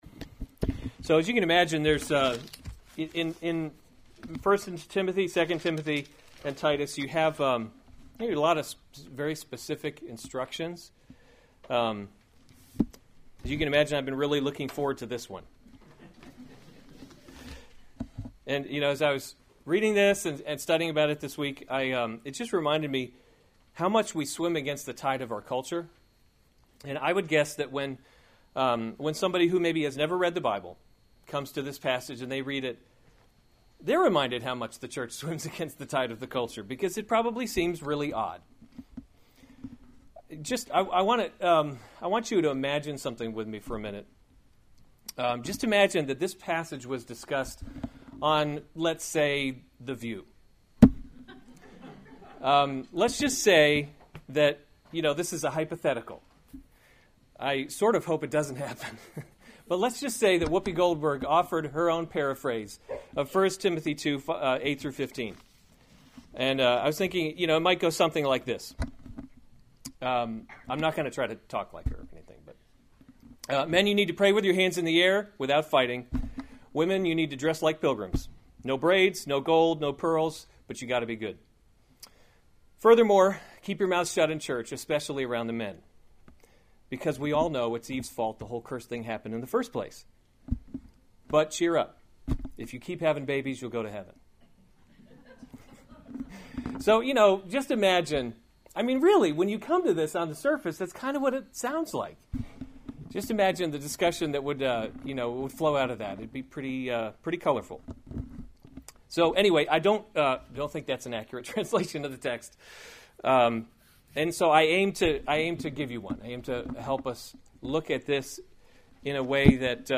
March 11, 2017 1 Timothy – Leading by Example series Weekly Sunday Service Save/Download this sermon 1 Timothy 2:8-15 Other sermons from 1 Timothy 8 I desire then that in every […]